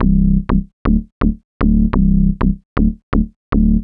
cch_bass_scatter_125_Dm.wav